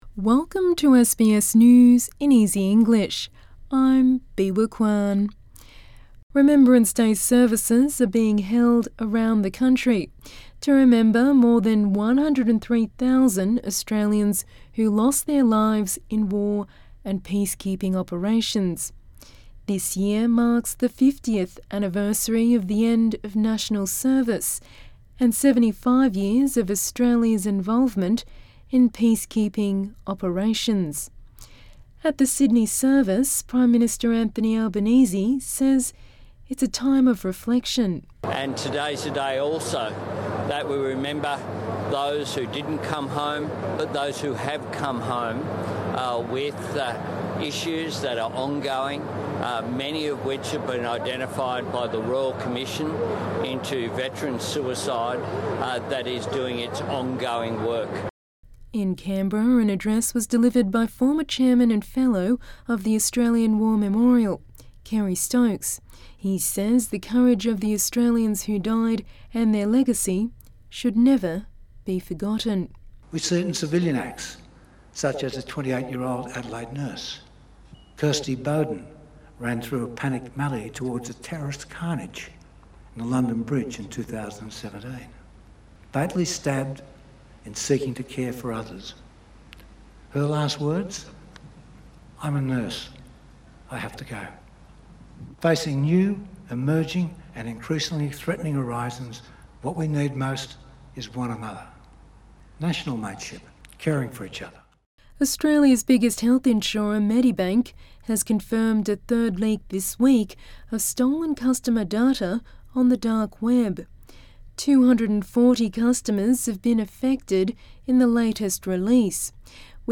A daily five minute news wrap for English learners and people with disability.